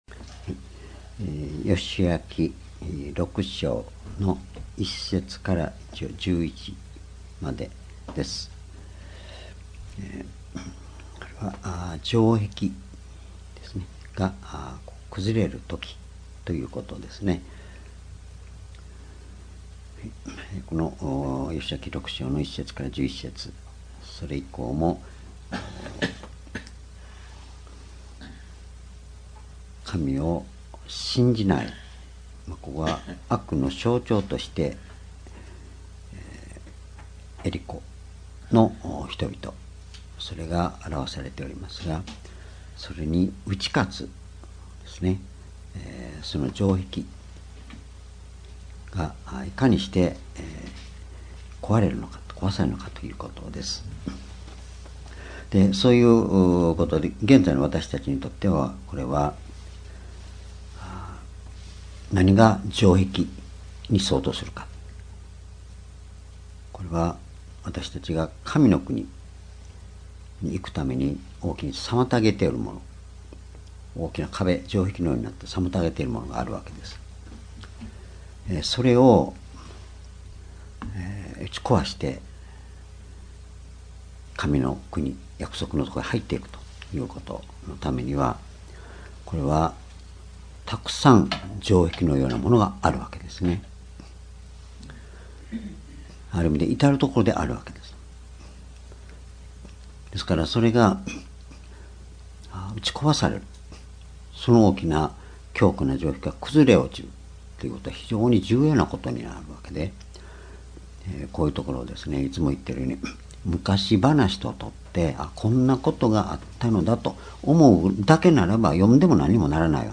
夕拝日時 2018年12月4日 夕拝 聖書講話箇所 「城壁が崩れるとき」 ヨシュア記6章1節～11節 ※視聴できない場合は をクリックしてください。